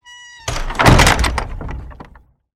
DoorClose1.wav